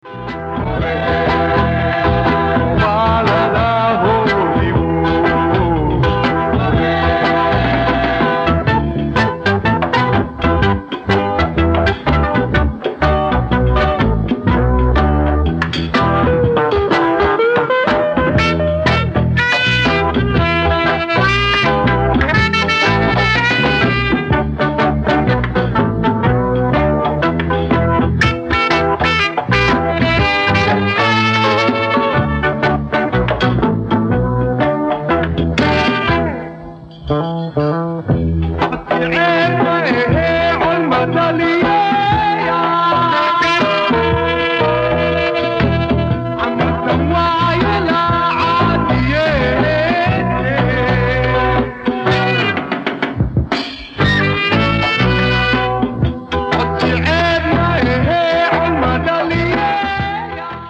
Digitized from cassettes